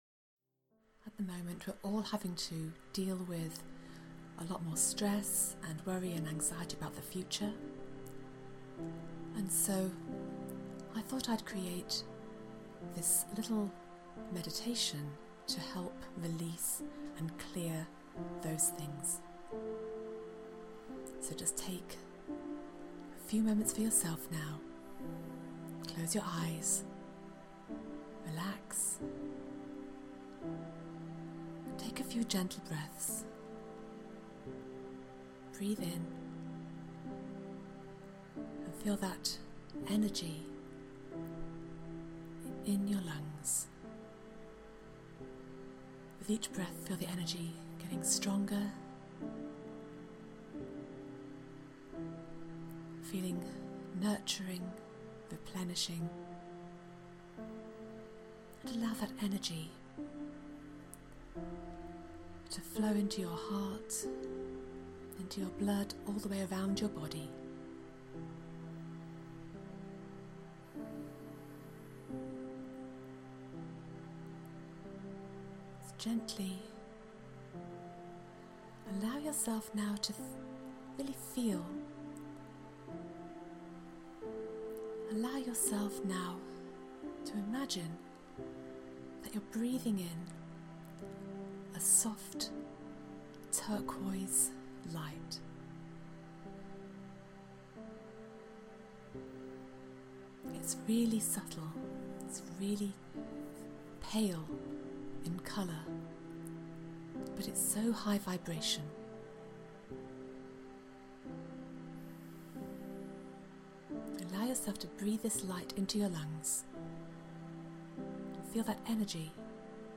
Turquoise De-stressing Meditation
Turquoise-Healing-Music.mp3